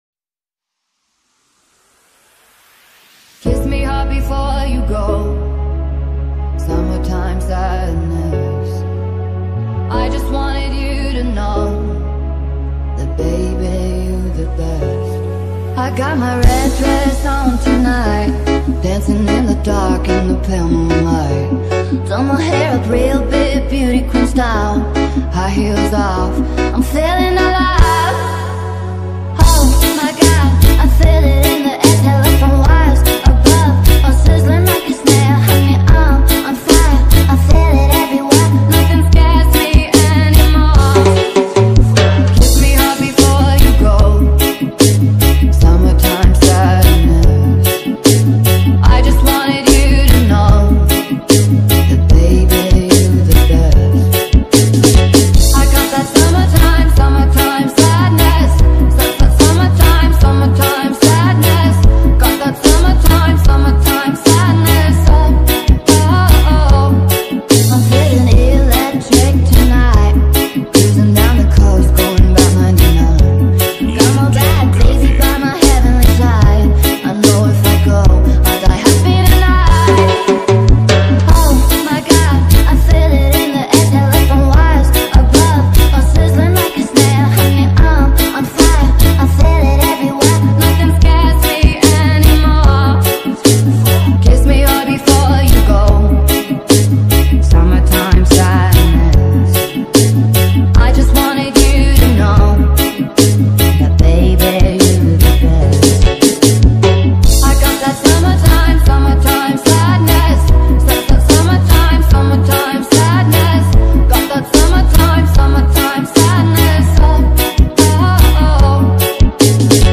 2025-02-13 16:27:52 Gênero: Reggae Views